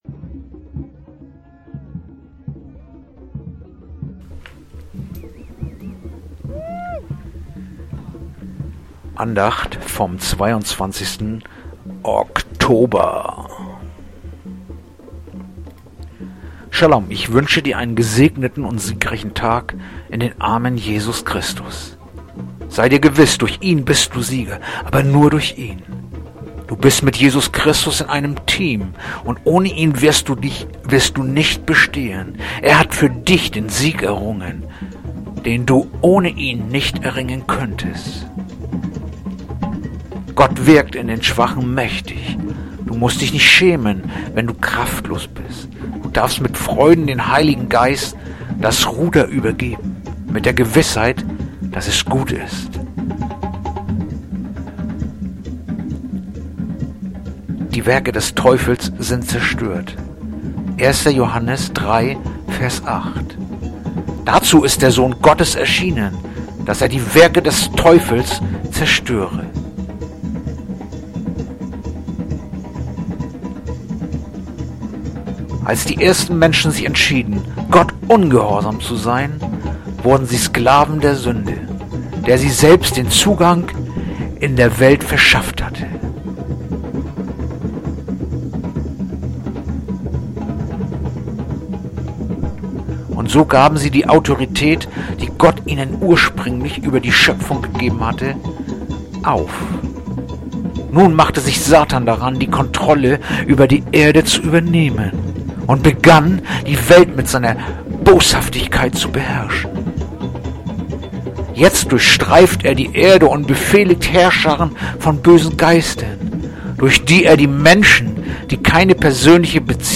Andacht-vom-22-Oktober-1-Johannes-3-8.mp3